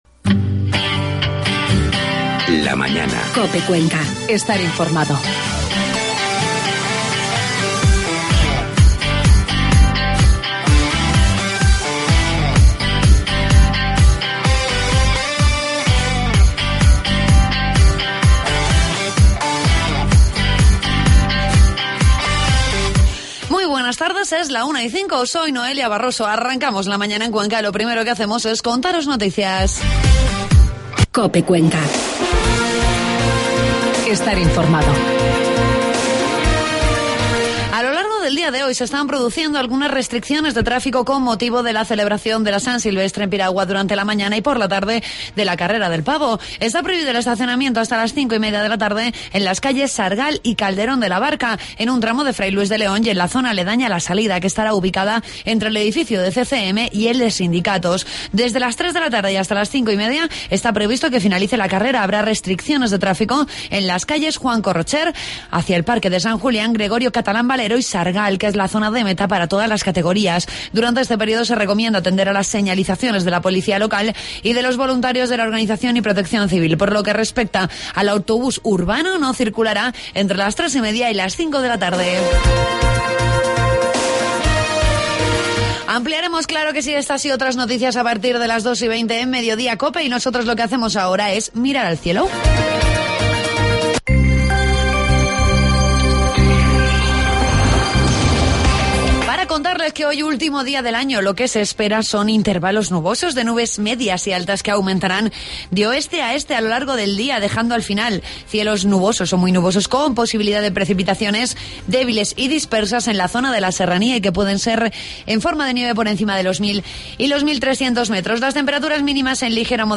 Entrevistamos a dos integrantes del coro de Alonso Lobo para conocer todos los detalles del concierto de inicio del año que se va a celebrar en la capilla de las monjas de la Puerta de Valencia.